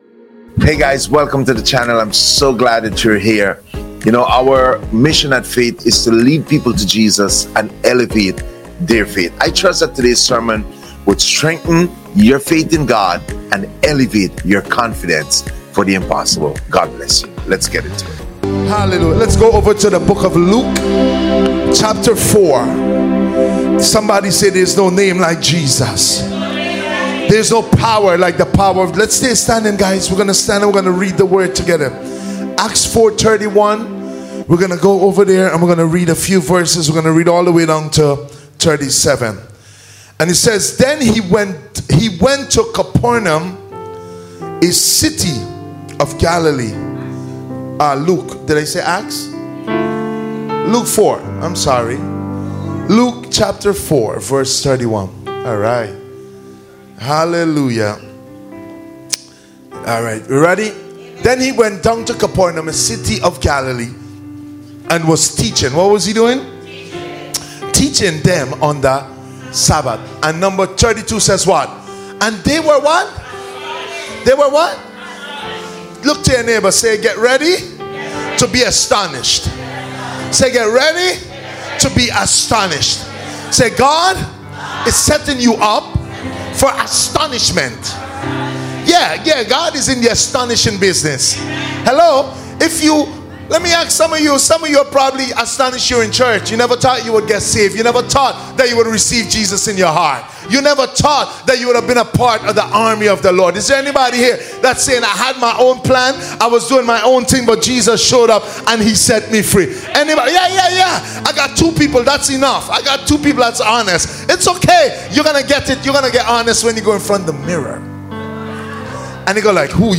Sermons | Faith Church